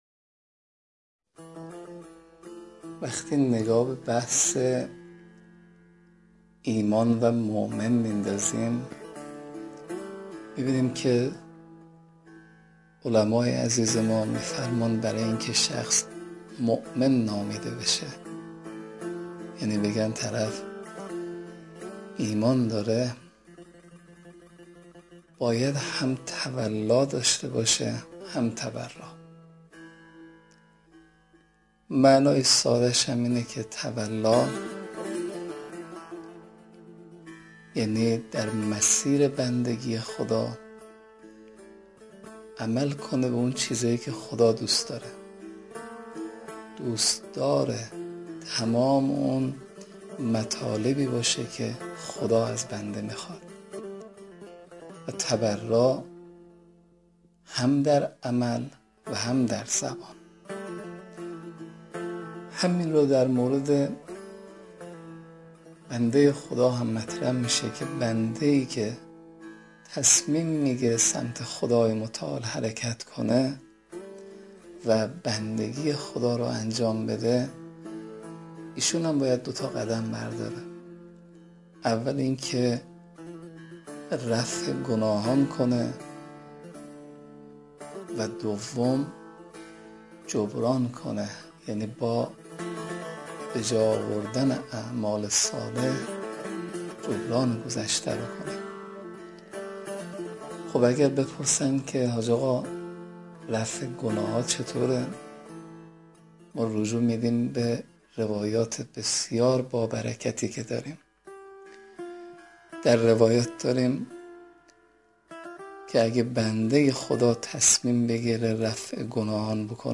دانلود شرح دعای روز پنجم ماه مبارک رمضان سخنران